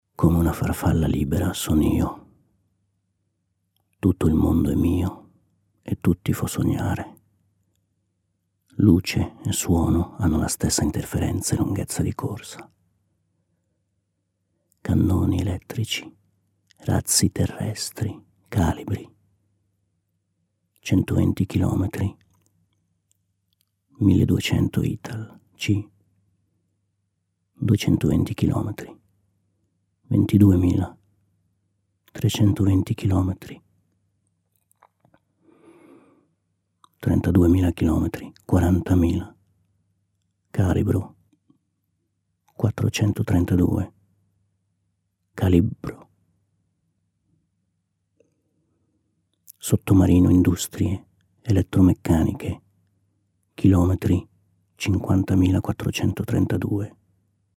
Lecture d'un texte de Nannetti